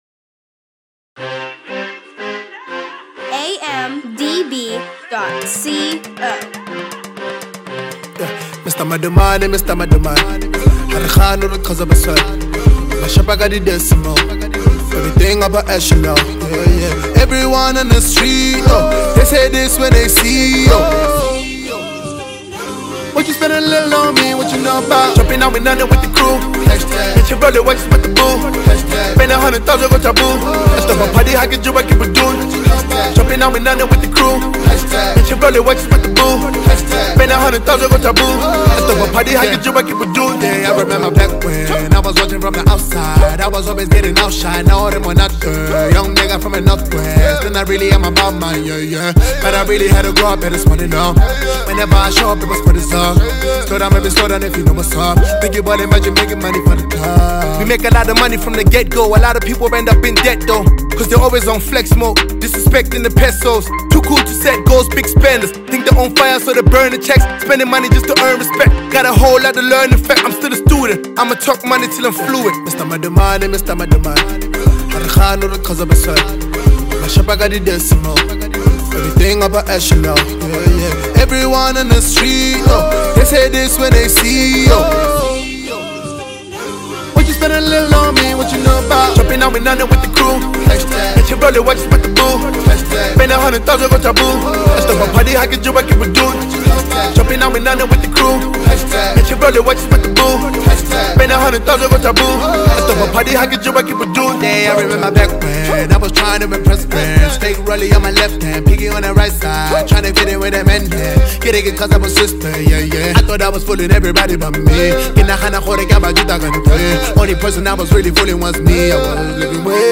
A feel good joint